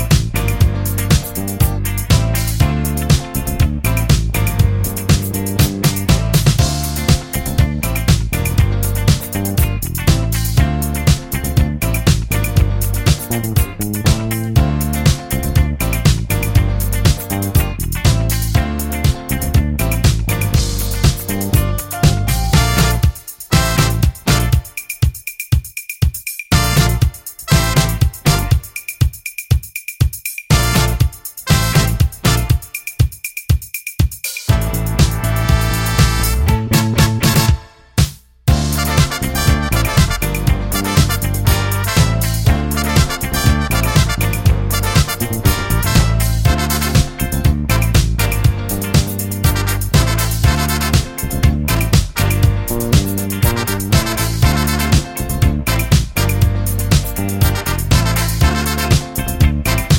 no Backing Vocals Disco 4:38 Buy £1.50